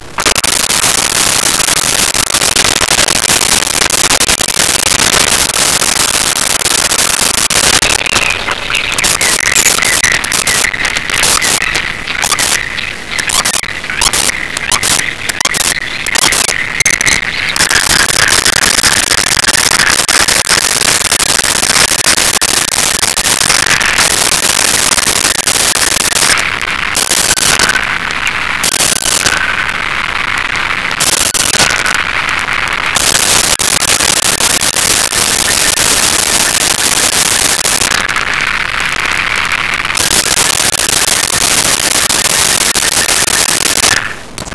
Black Woodpecker Pecks Chirps
Black Woodpecker Pecks Chirps.wav